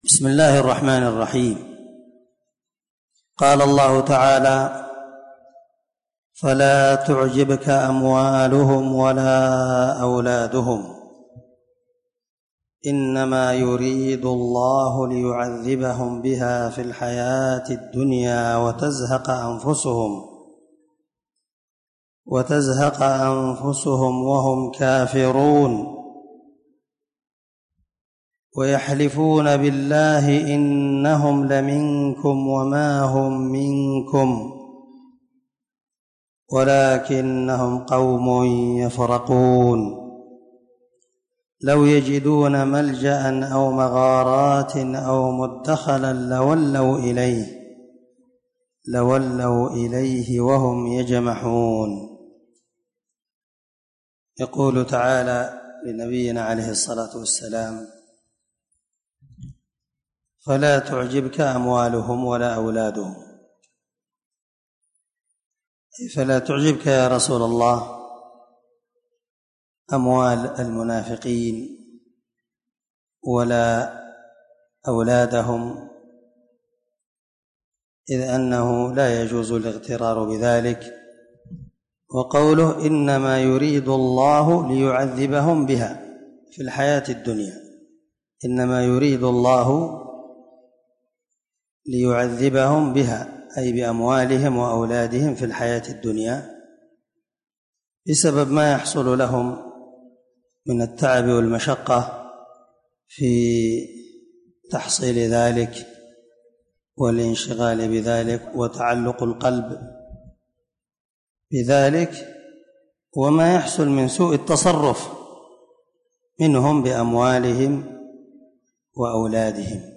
554الدرس25تفسير آية ( 55_57) من سورة التوبة من تفسير القران الكريم مع قراءة لتفسير السعدي
دار الحديث- المَحاوِلة- الصبيحة.